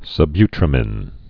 (sə-bytrə-mĭn, -mēn)